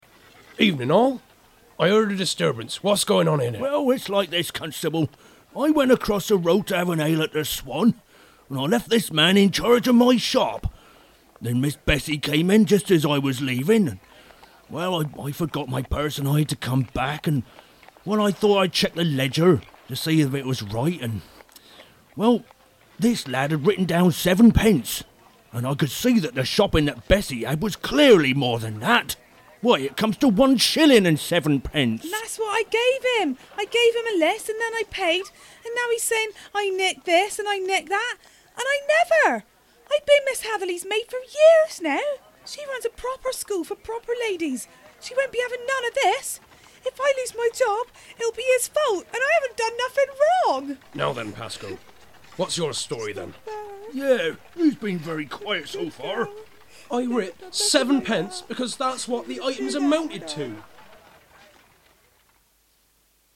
Stokes Croft Radio Play